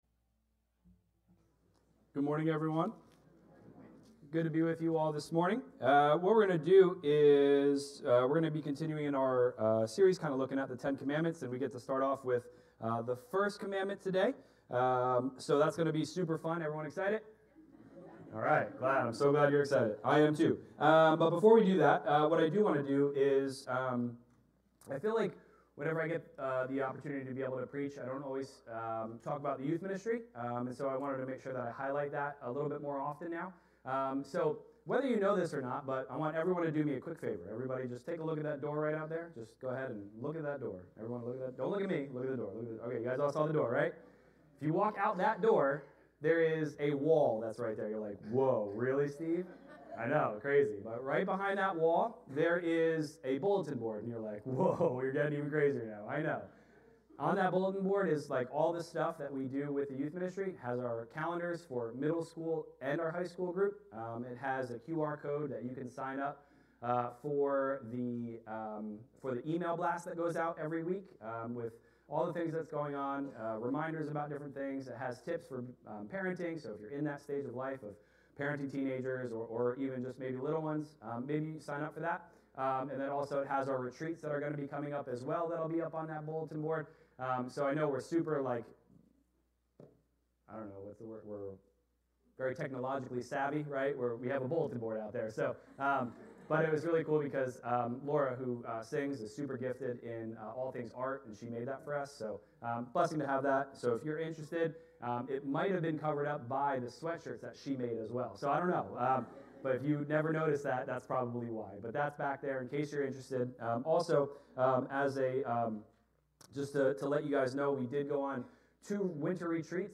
Sermons | Forked River Baptist Church